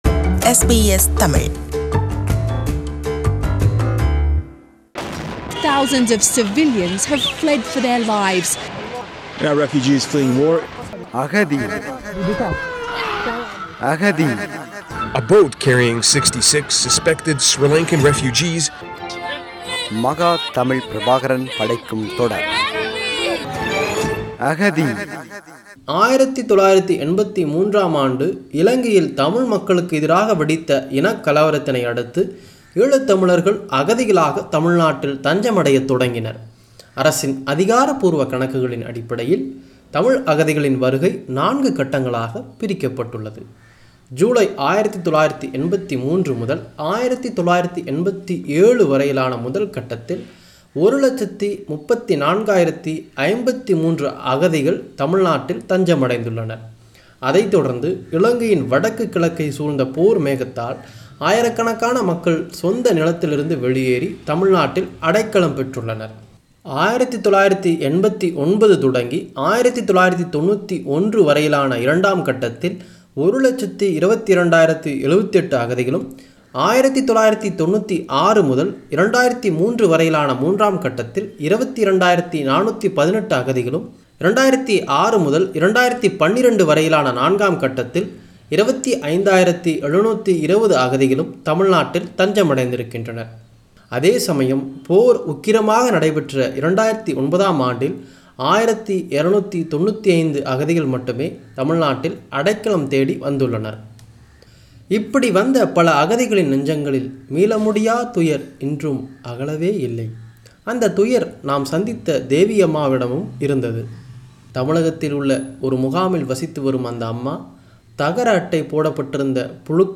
In this part, the refugees explain the support of Tamil Nadu government in the form of by providing free living space and basic amenities and also brief the functionality. How the Tamil Nadu people treat Tamil refugees from Sri Lanka ?